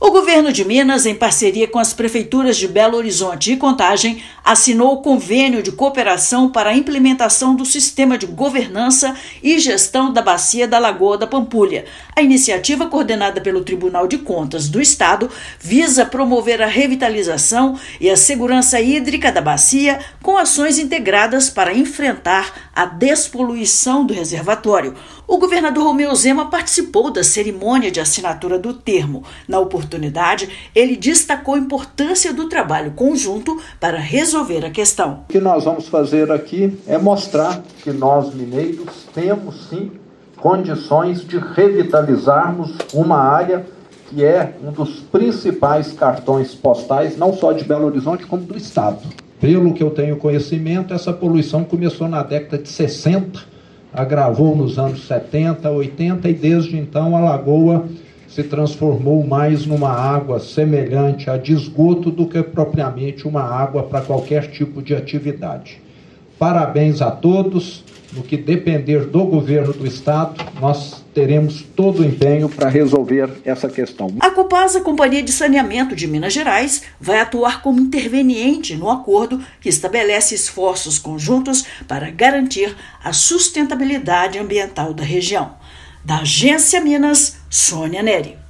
[RÁDIO] Governo de Minas e prefeituras de BH e Contagem assinam acordo para revitalização da Lagoa da Pampulha
Convênio foi coordenado pelo TCE-MG, que busca integrar esforços para despoluição e segurança hídrica do reservatório. Ouça matéria de rádio.